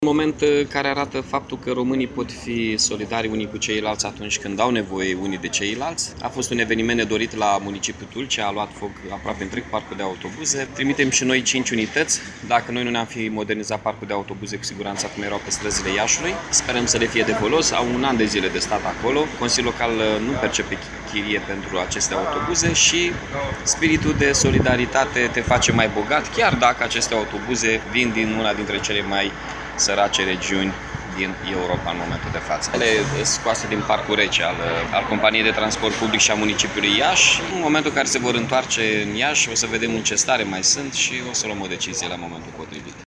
Primarul municipiului Iasi, Mihai Chirica